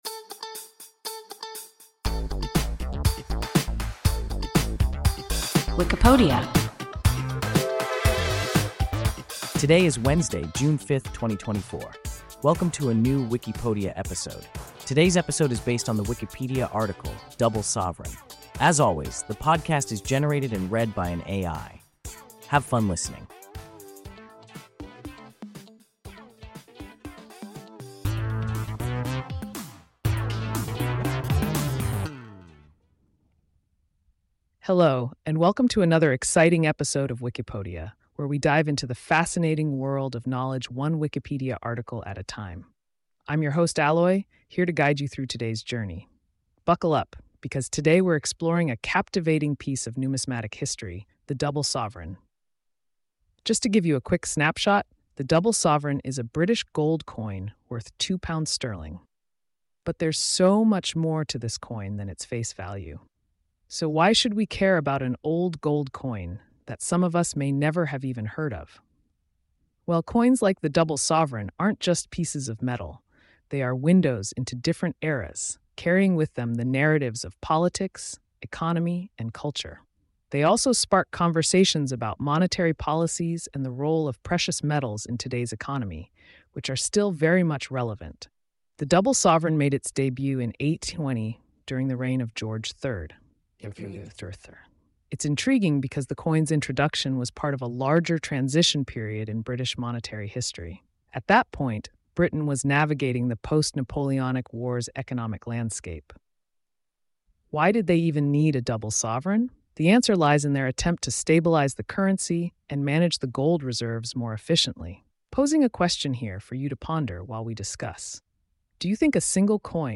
Double sovereign – WIKIPODIA – ein KI Podcast